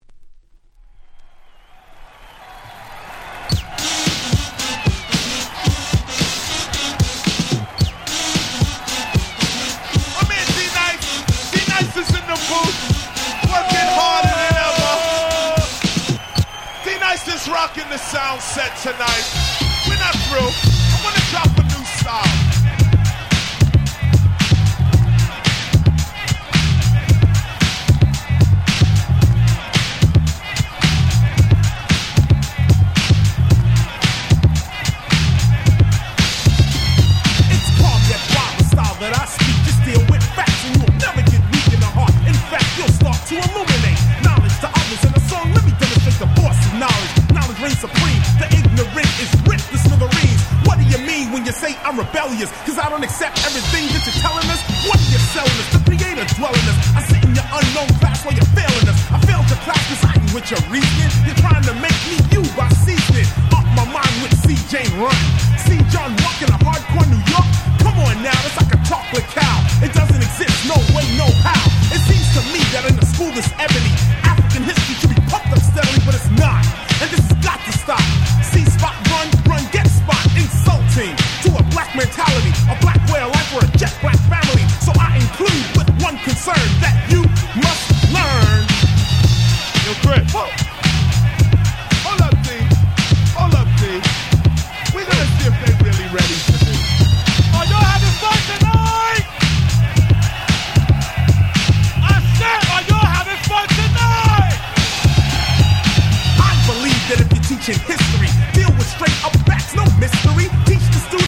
【Media】Vinyl 12'' Single
89' Smash Hit Hip Hop !!
89年リリースですが80年代初頭のOld School Rapを彷彿とされるDisco Rapチューンで最高！！